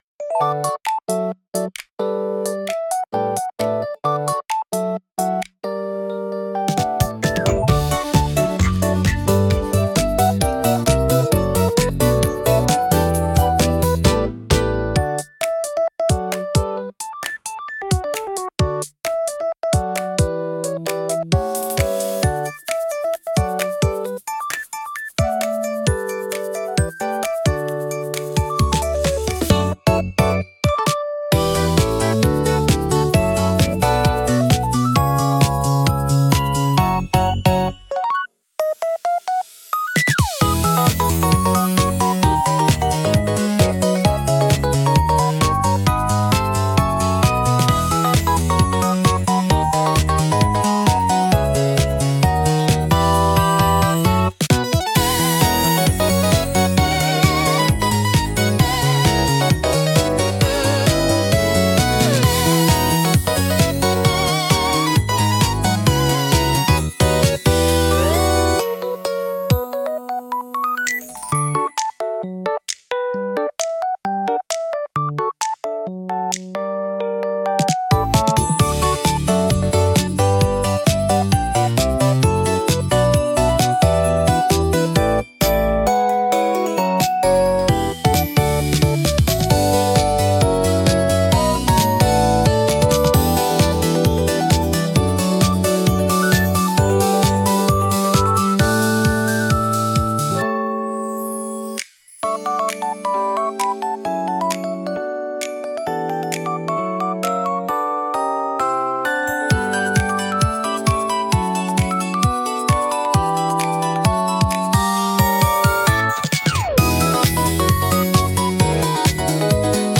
ゆめかわいいフリーBGM🧸🎧🫧
ゆめかわポップBGM